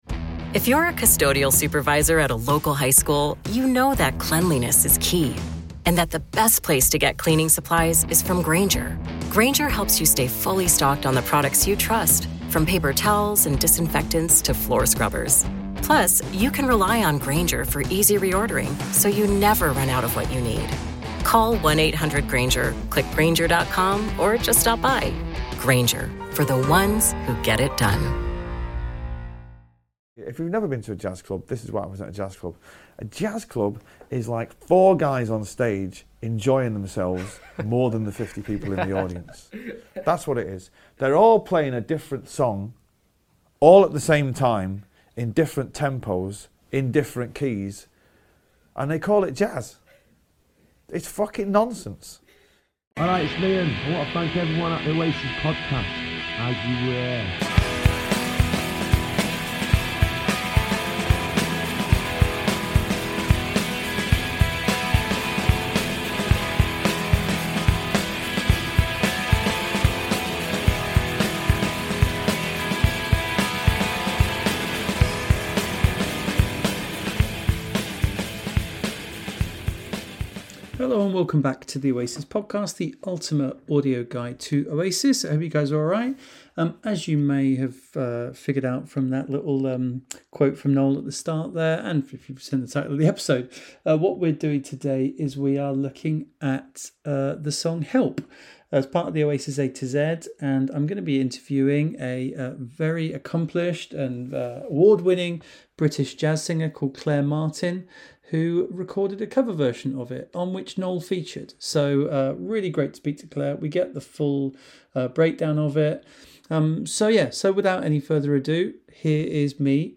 Oasis A-Z - Help! Review With Legendary Jazz Singer Claire Martin